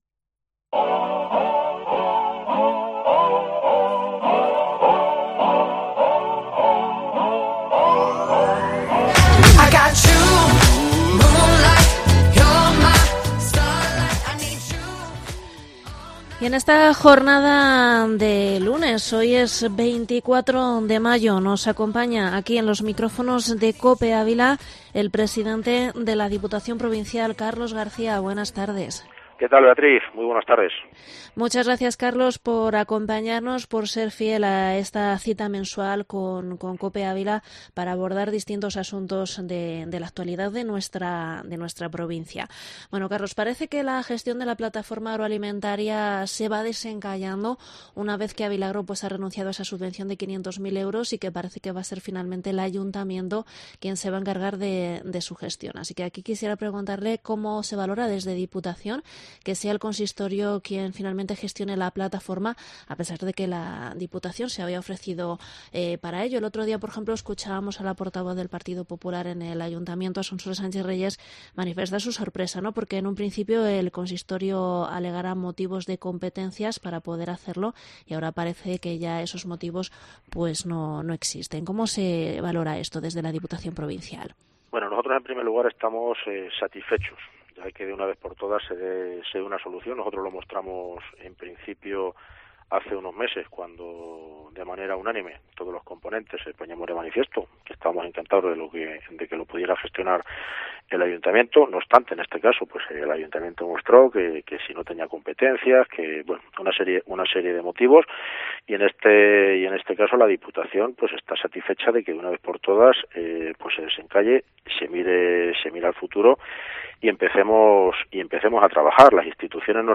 Entrevista al presidente de Diputación en Mediodía Cope Ávila 24/05/2021